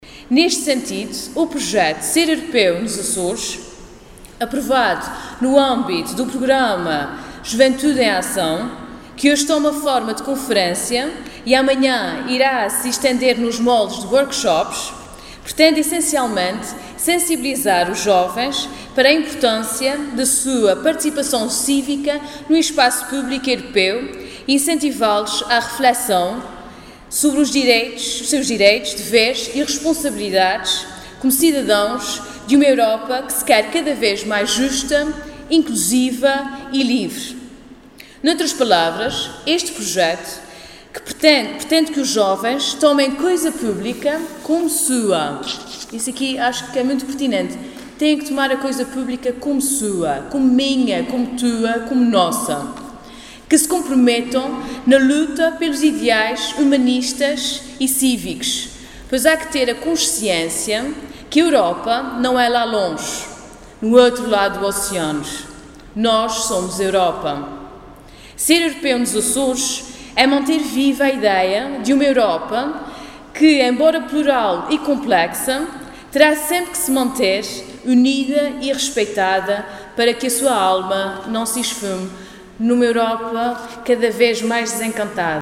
Pilar Damião de Medeiros falava na conferência "Ser Europeu nos Açores", que decorreu sexta-feira à noite no Teatro Micaelense, em Ponta Delgada, com a presença de cerca de meia centena de participantes.